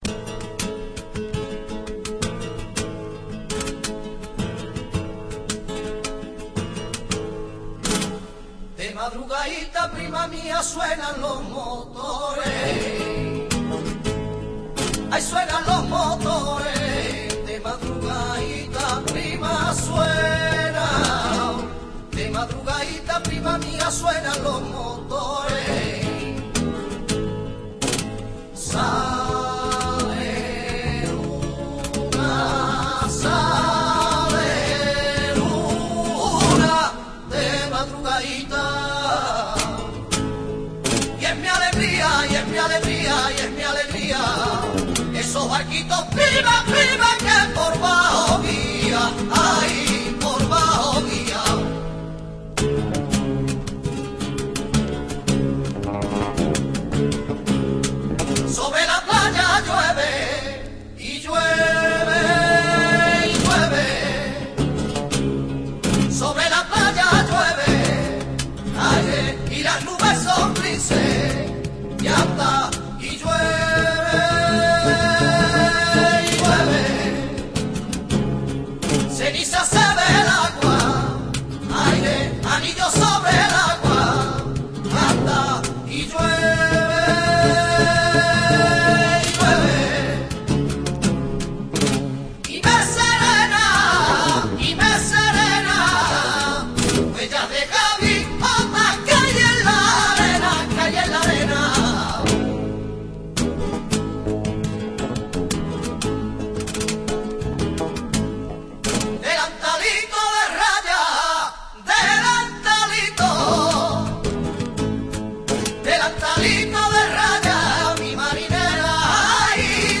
Temática: marinera